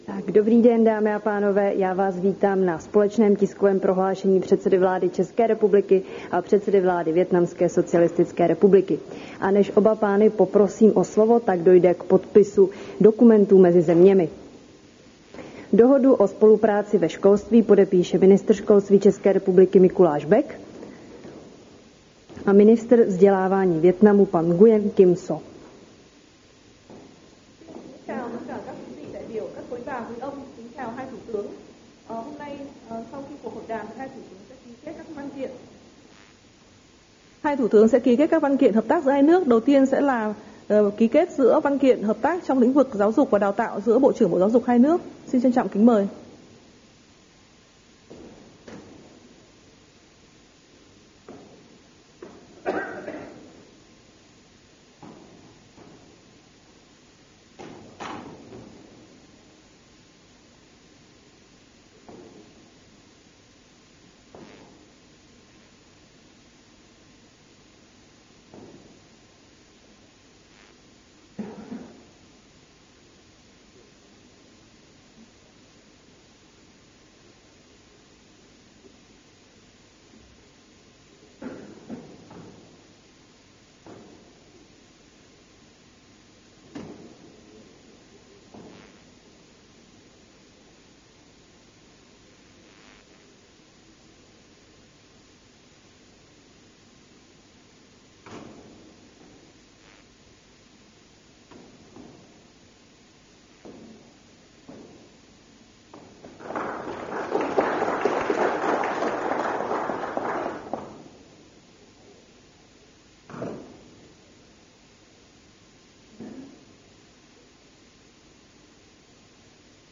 Společné tiskové prohlášení předsedy vlády ČR a předsedy vlády Vietnamské socialistické republiky